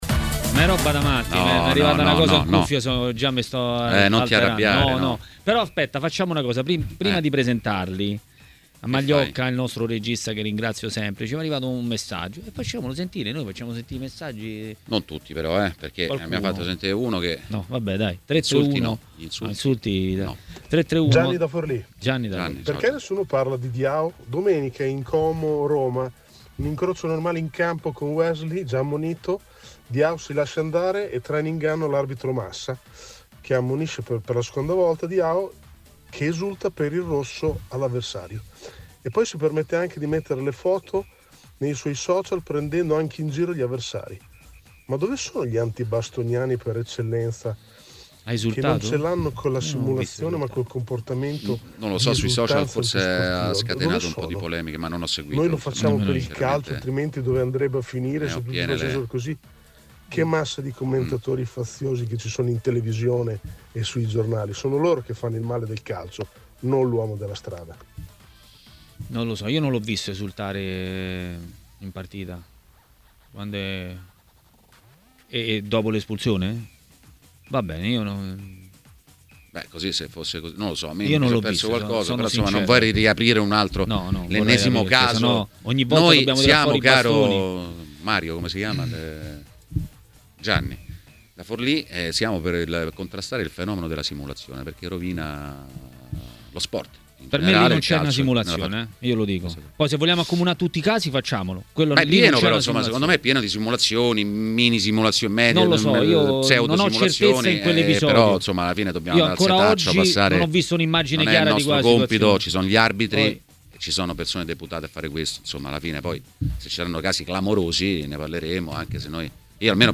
Le Interviste
ai microfoni di Tmw Radio